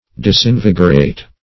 Search Result for " disinvigorate" : The Collaborative International Dictionary of English v.0.48: Disinvigorate \Dis`in*vig"or*ate\, v. t. To enervate; to weaken.